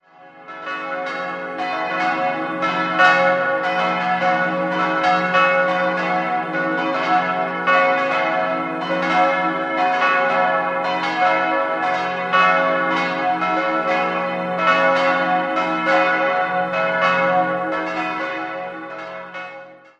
4-stimmiges ausgefülltes Cis-Moll-Geläute: cis'-e'-fis'-gis'